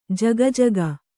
♪ jagajaga